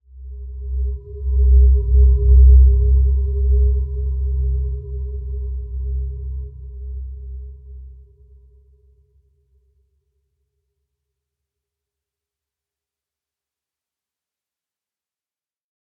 Dreamy-Fifths-C2-f.wav